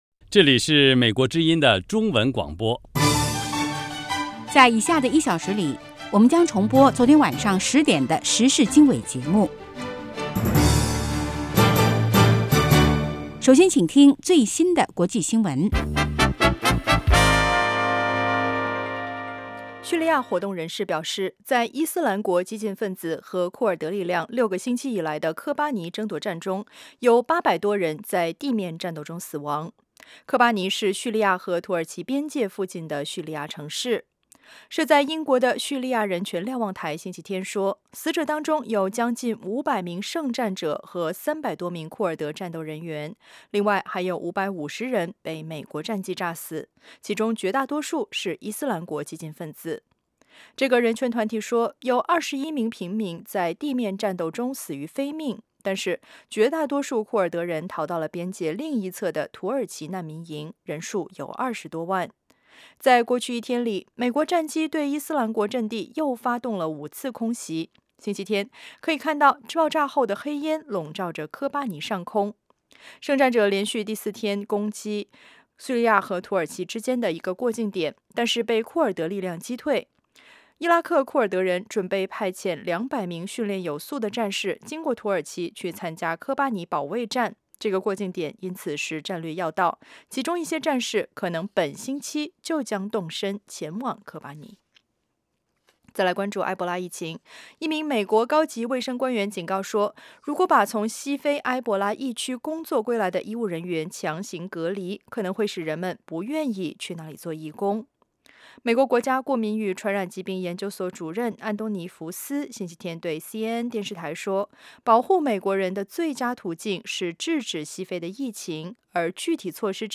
早8-9点广播节目